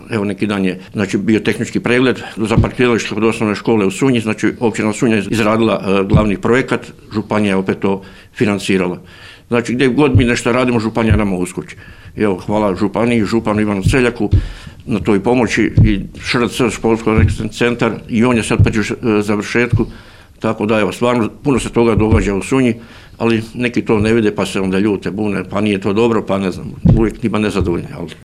Suradnja Općine Sunje i Sisačko-moslavačke županije itekako je dobra, ocjenuje to načelnik Grga Dragičević te dodaje